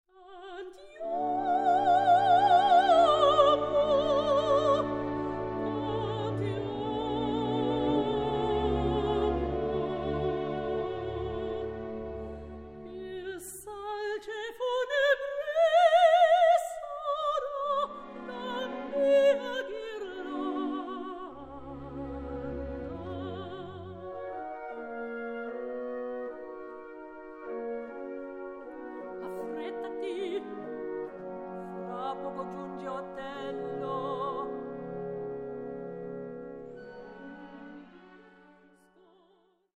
ravishing Australian soprano
Soprano